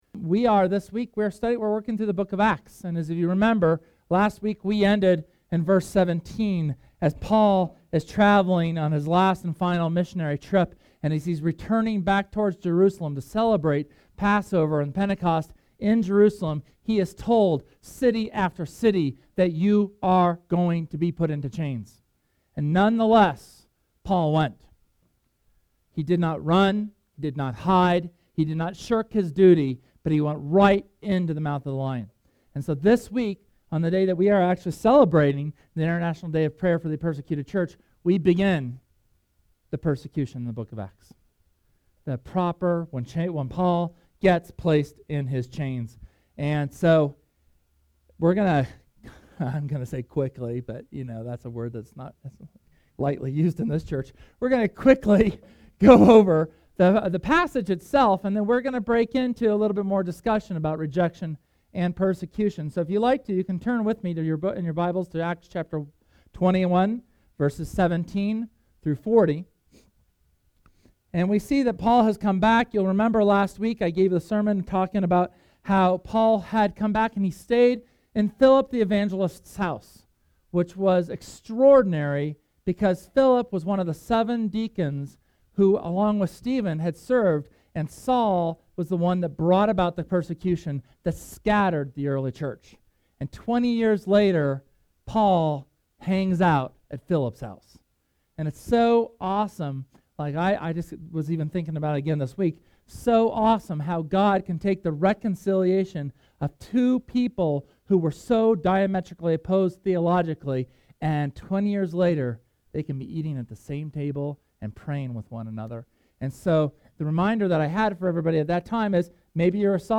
SERMON: Theology of Suffering
Sermon on Acts chapter 22 where Paul gets mobbed by the crowd in Jerusalem.